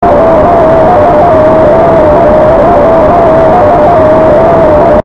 Nightmare Jumpscare Sound Effect Free Download
Nightmare Jumpscare